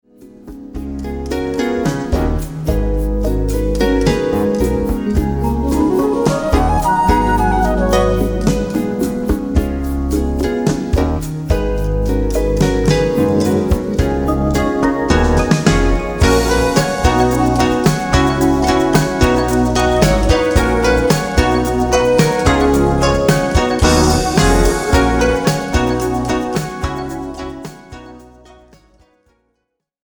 Recorded spring 1986 at the Sinus Studios, Bern Switzerland